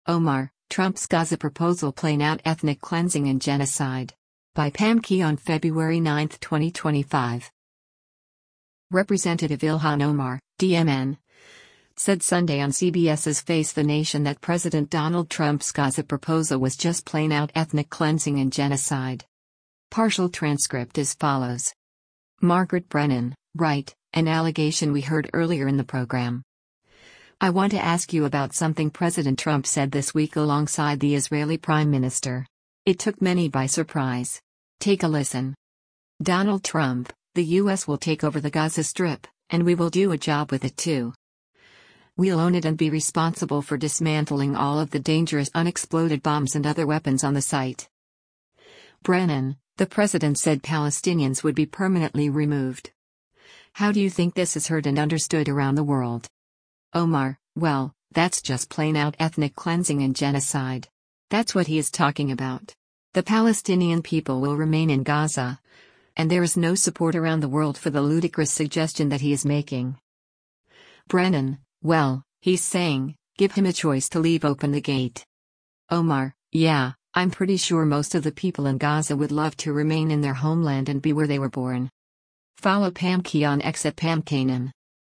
Representative Ilhan Omar (D-MN) said Sunday on CBS’s “Face the Nation” that President Donald Trump’s Gaza proposal was “just plain out ethnic cleansing and genocide.”